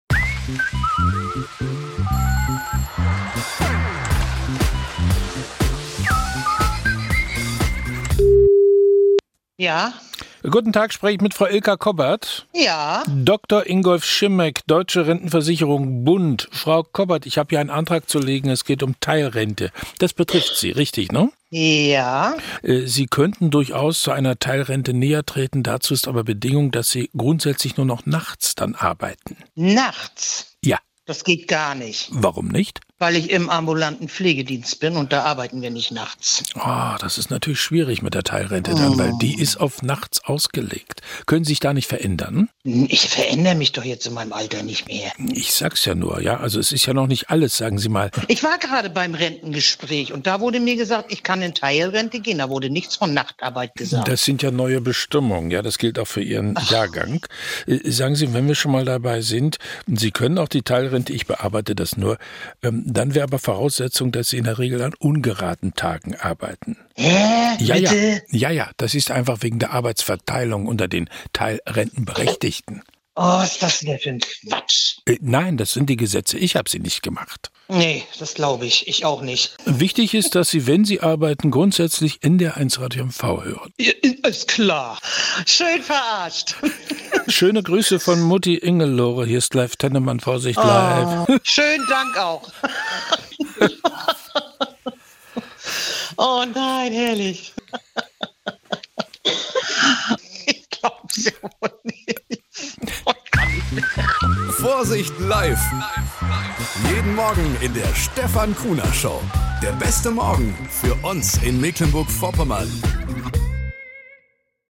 1 Morgenandacht auf NDR 1 Radio MV 1:46